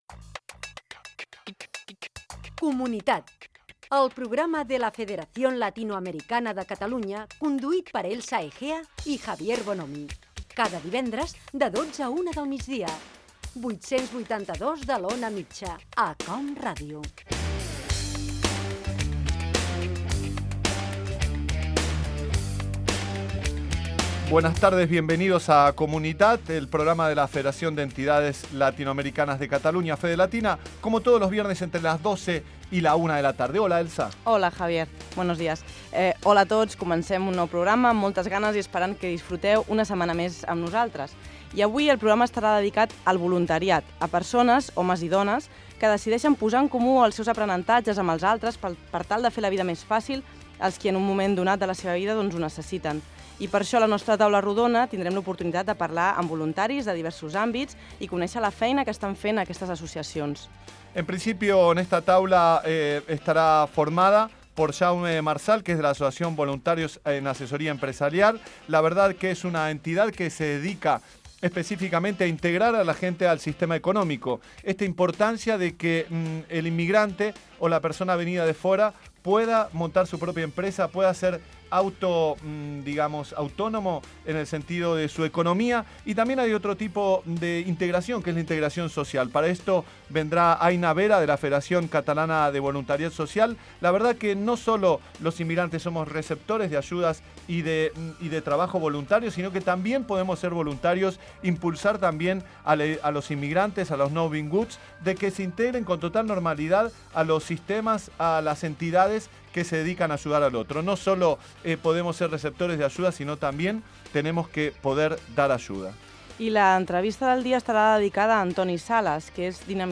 Careta, presentació i sumari del programa
Divulgació